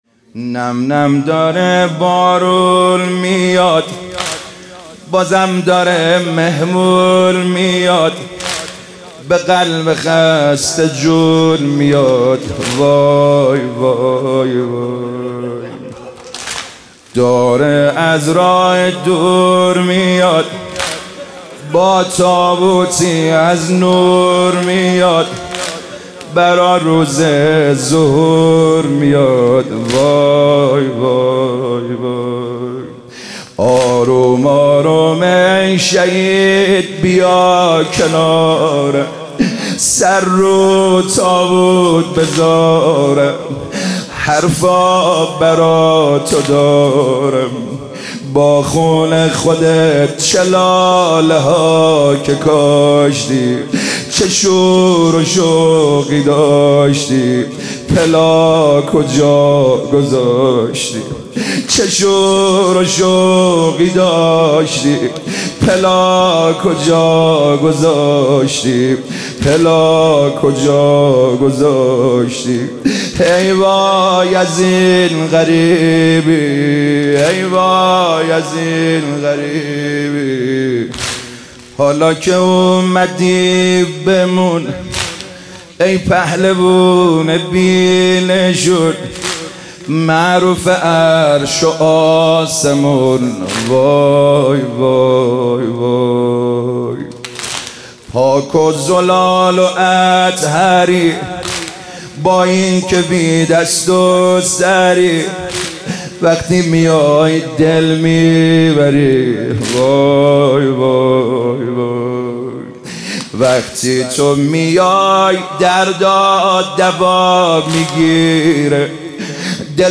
ایام فاطمیه 1399 | هیئت کربلا رفسنجان